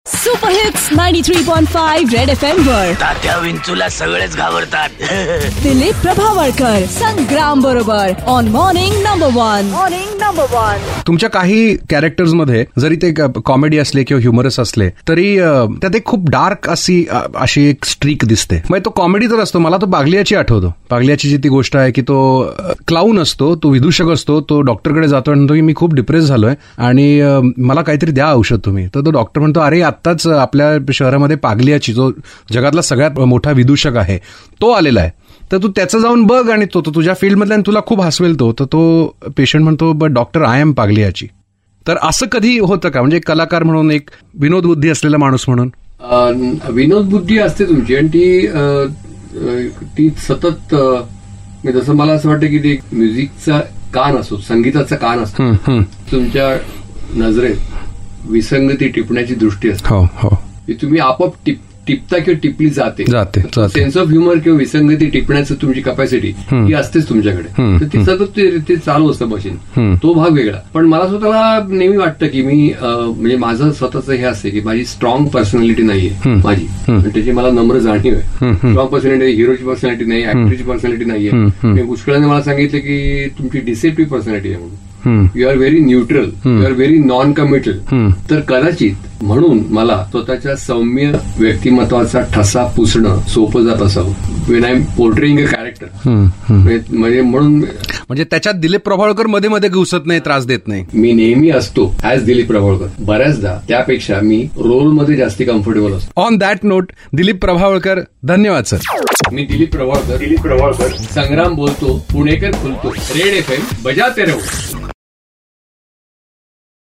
Vetran Marathi Actor Dilip Prabhavalkar in a candid chat